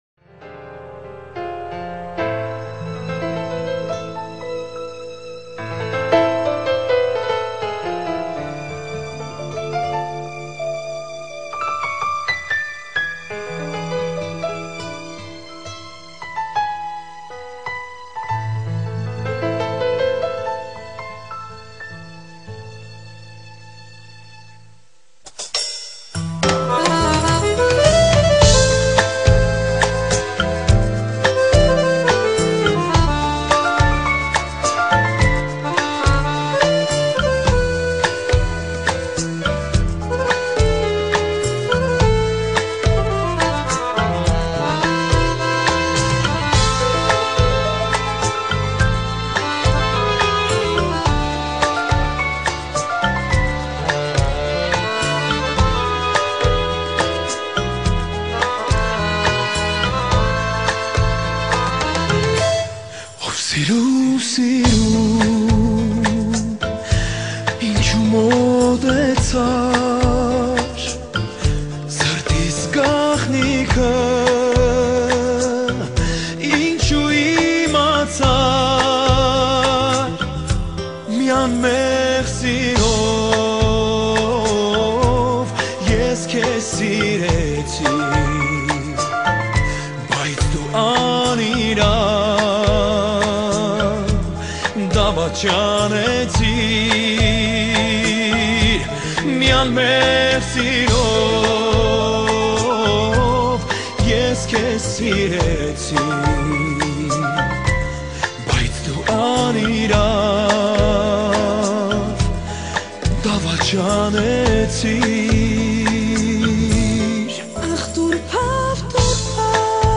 Armenian Folk